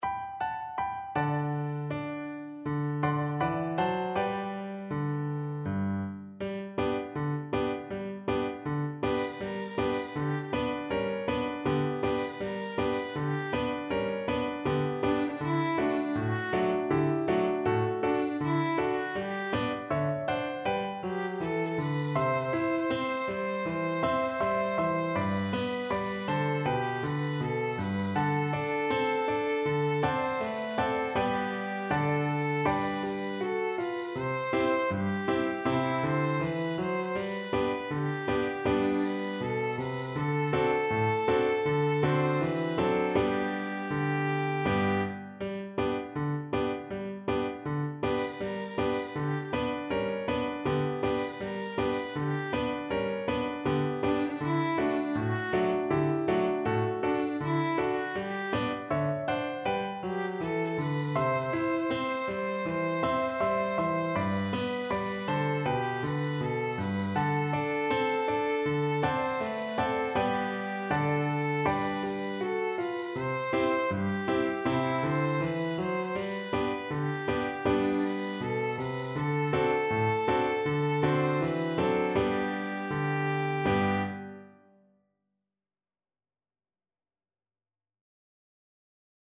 Violin
4/4 (View more 4/4 Music)
G major (Sounding Pitch) (View more G major Music for Violin )
Humorously, two in a bar swing =c.80
D5-C6
Traditional (View more Traditional Violin Music)